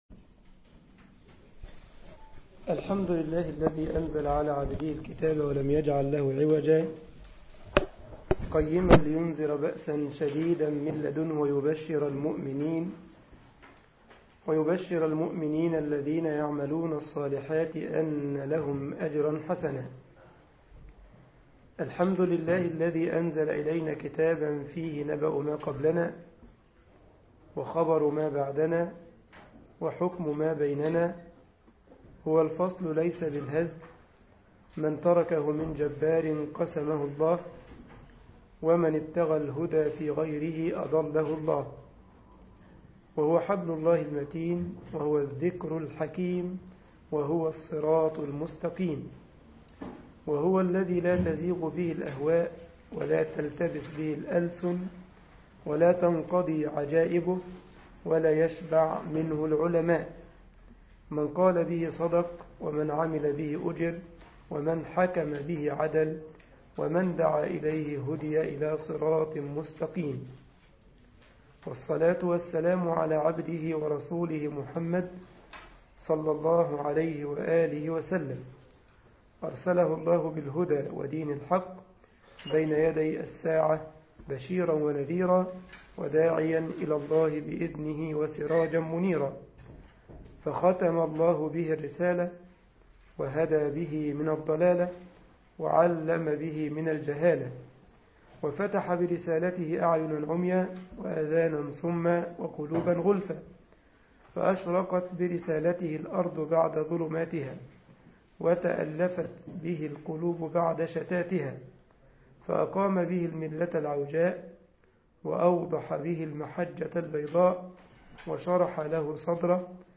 مسجد الجمعية الإسلامية بالسارلند ـ ألمانيا